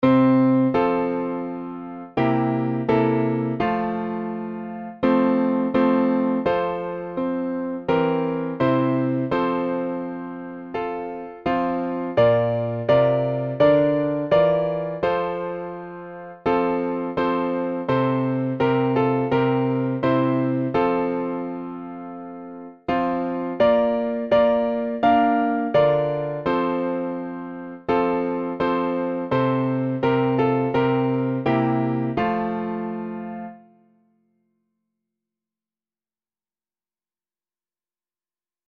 トーン、階段、歯ブラシ テンポ良く Micronesia http